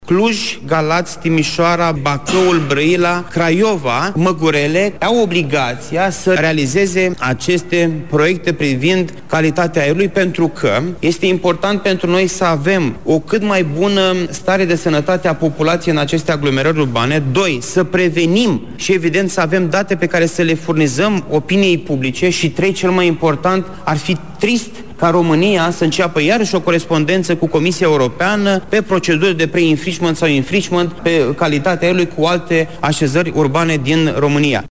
Costel Alexe a mai spus că, pe lângă cele trei oraşe, România riscă să mai aibă alte 10 municipii în situaţia de preinfringement: